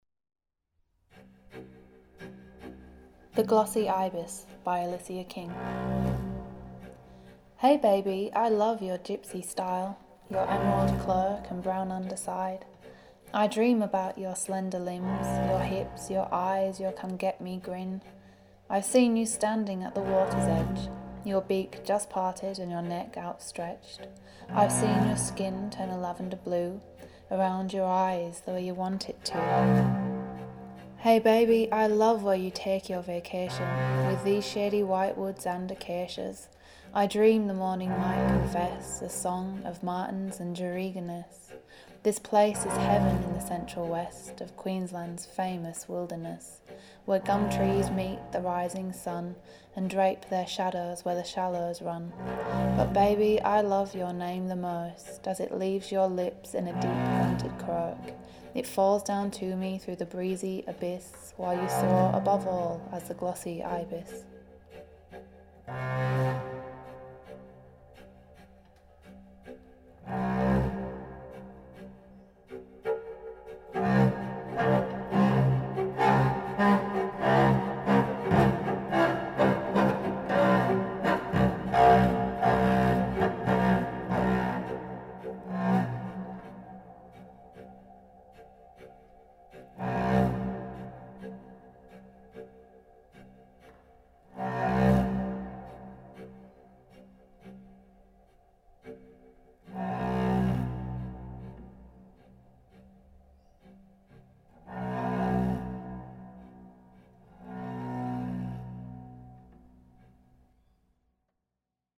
Glossy Ibis call
Cello